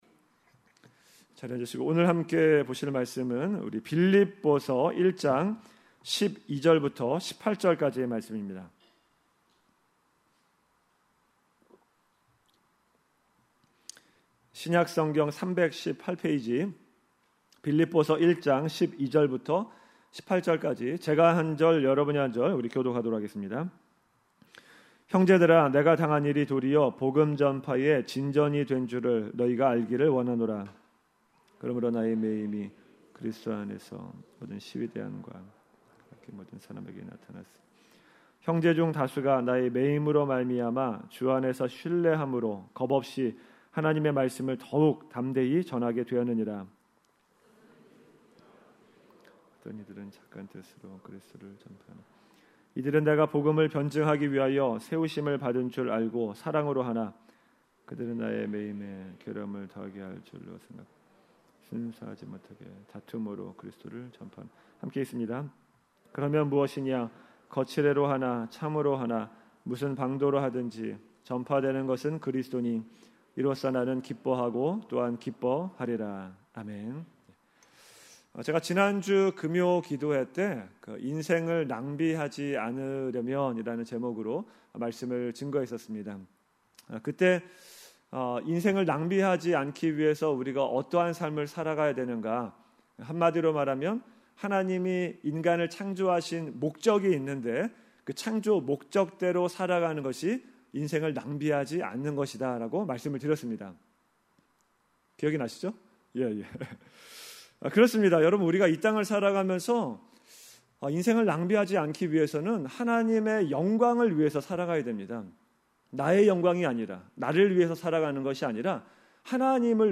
Like this: Like Loading... 2019년 금요설교 Uncategorized 2026년 전교인 수련회 찬양 플레이 리스트 각종 신청서 2025년 헌금내역서 신청서